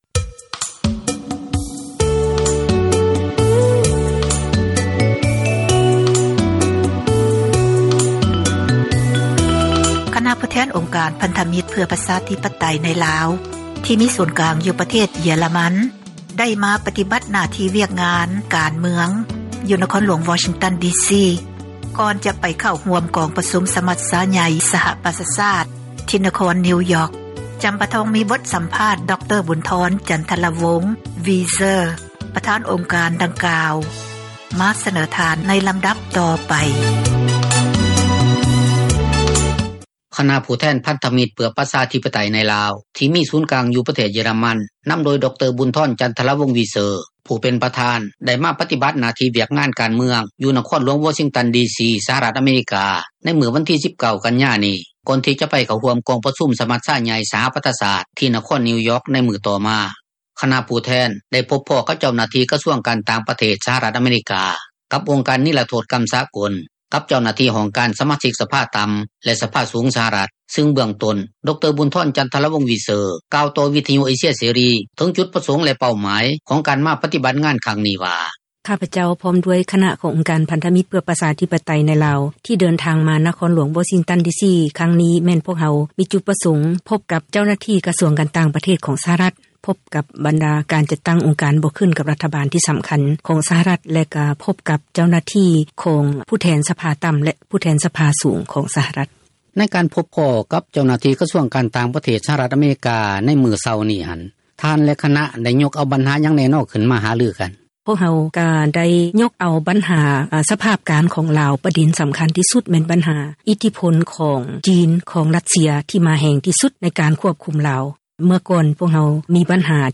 ສັມພາດ ພິເສດ ຄະນະຜູ້ແທນ ອົງການ ພັນທະມິຕ ເພື່ອປະຊາທິປະໄຕ ໃນລາວ